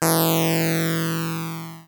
Hum17.wav